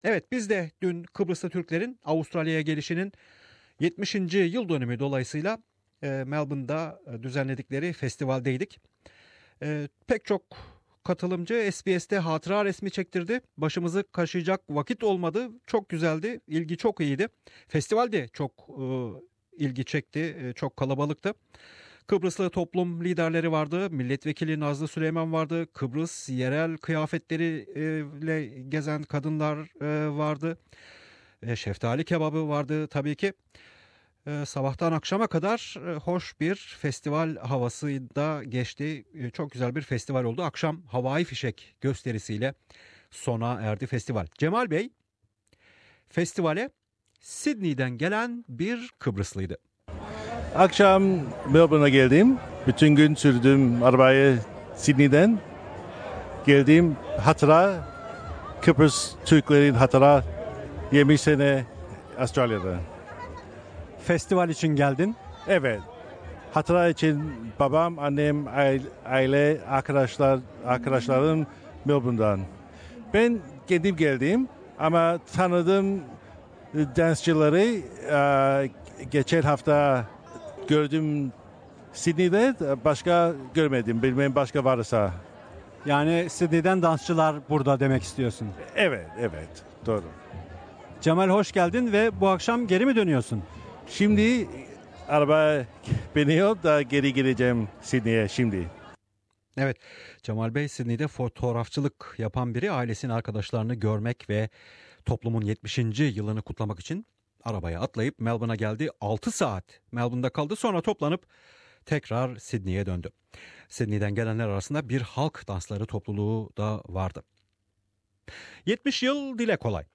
Kıbrıs Türk festivali alandan yaptığımız ses kayıtları